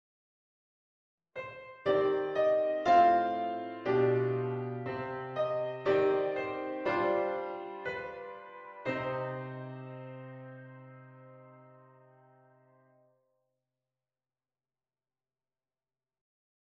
syncopendissonant